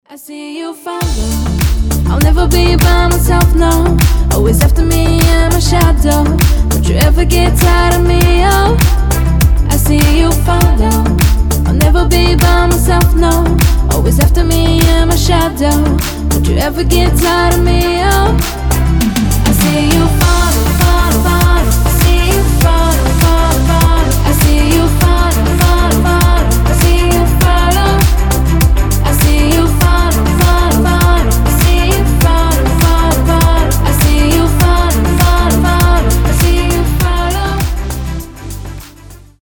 • Качество: 320, Stereo
ритмичные
женский голос